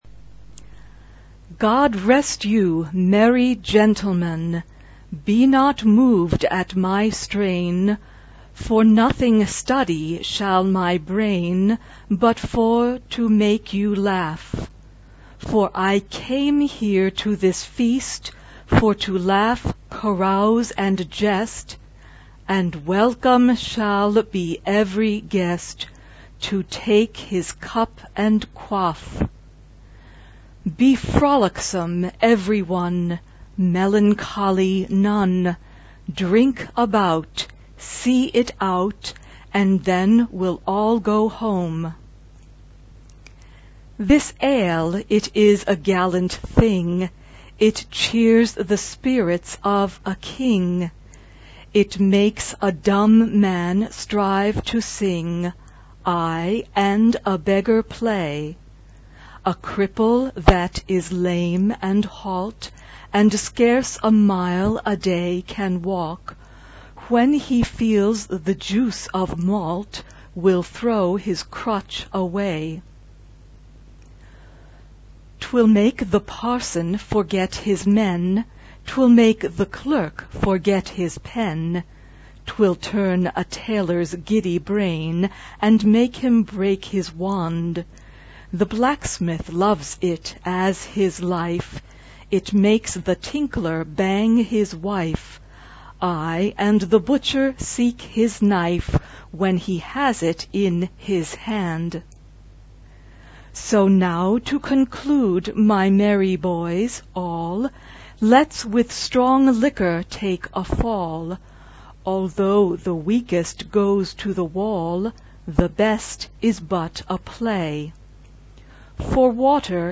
SAAB (4 voix mixtes) ; Partition complète.
Chant de Noël.
Instrumentation : Clavier (ad lib)
Tonalité : mi mineur